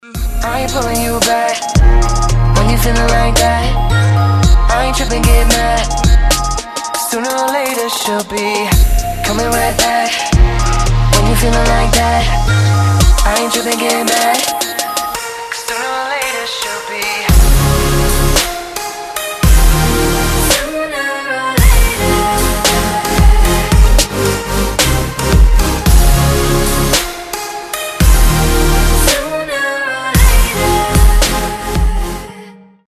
• Качество: 256, Stereo
мужской голос
Electronic
RnB
Bass